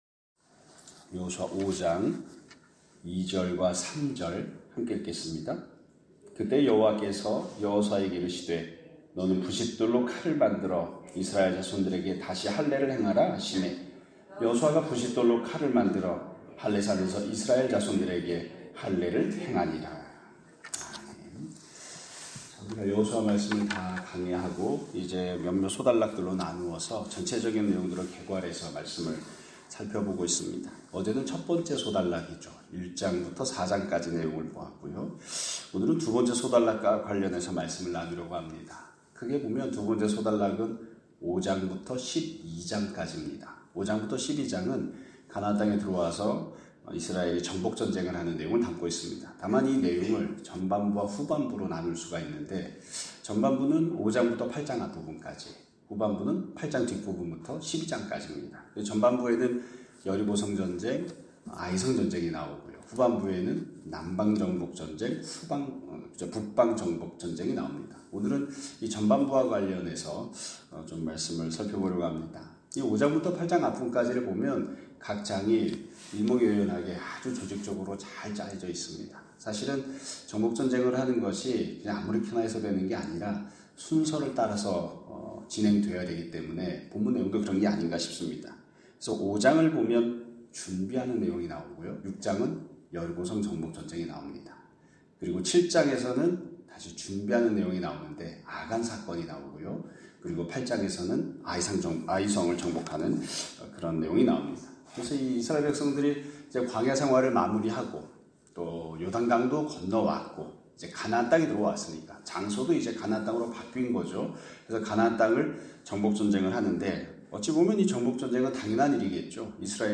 2025년 3월 11일(화요일) <아침예배> 설교입니다.